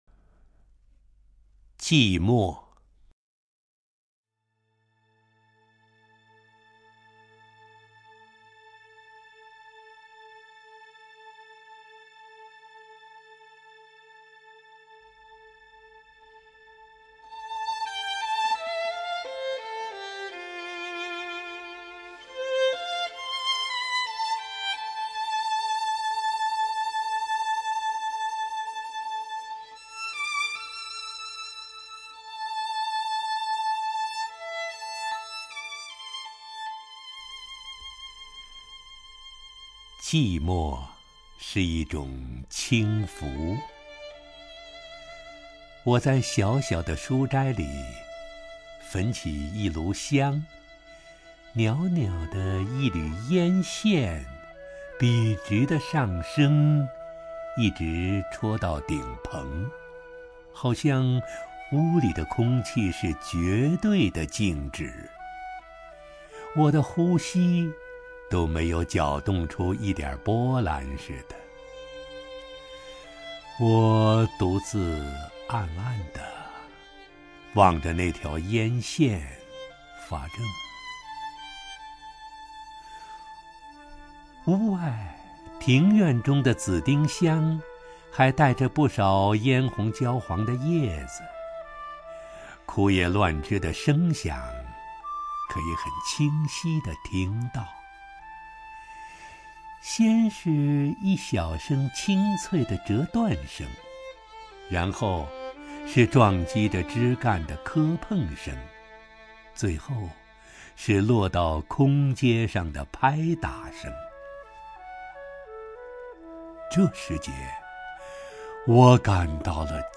首页 视听 名家朗诵欣赏 陈醇
陈醇朗诵：《寂寞》(梁实秋)　/ 梁实秋